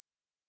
10000Hz.wav